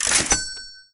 buySFX.wav